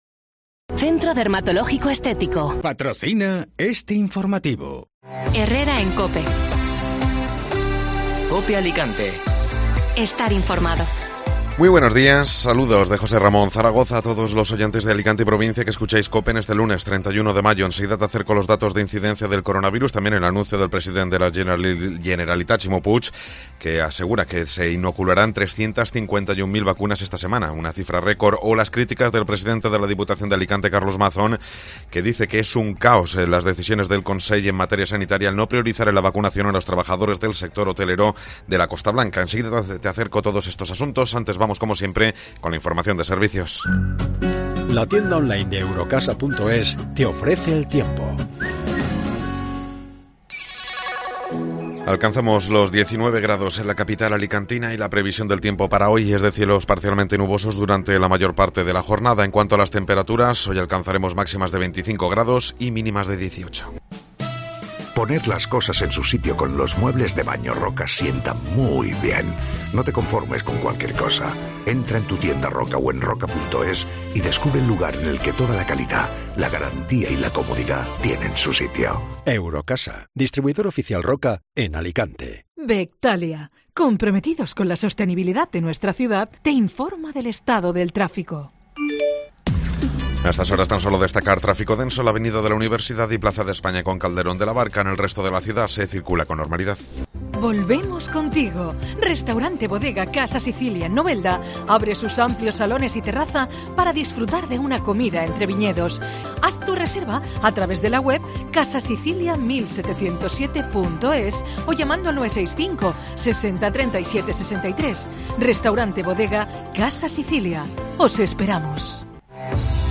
Informativo Matinal (Lunes 31 de Mayo)